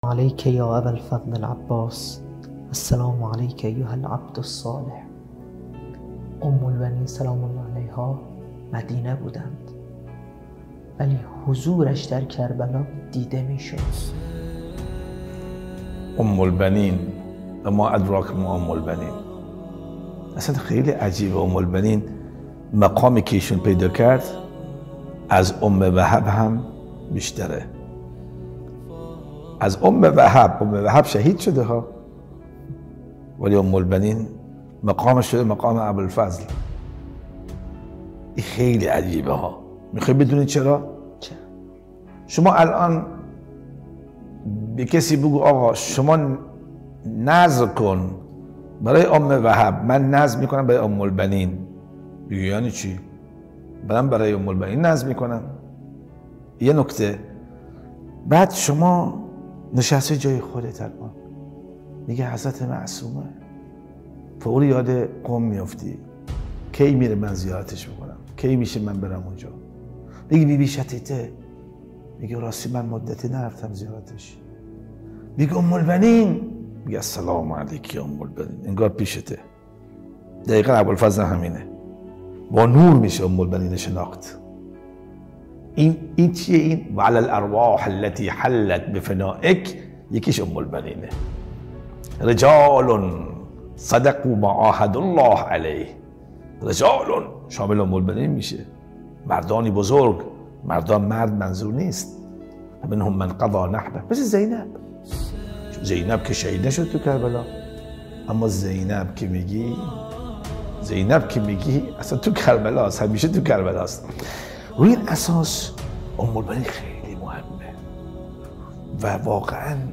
برنامه گفت و گویی حرف آخر
شب نهم محرم الحرام سال 1442 ه.ق